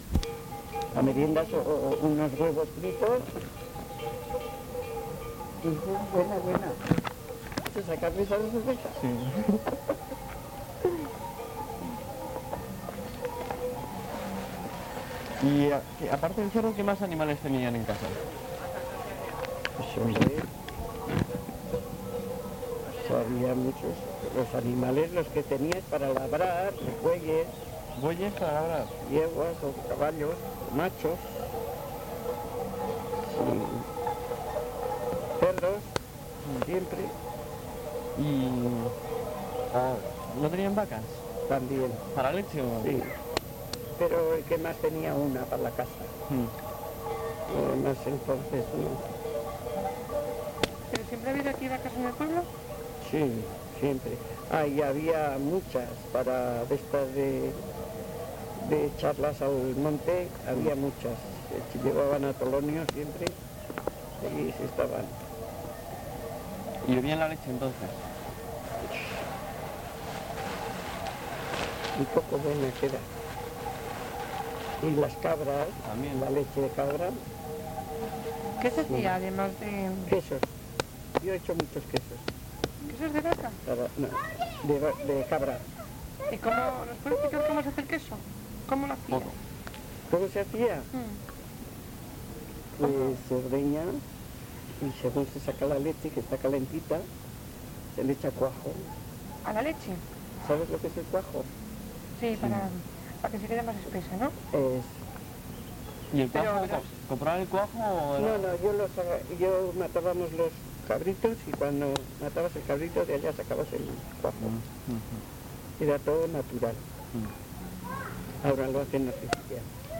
Locality Berganzo/Zambrana
mujer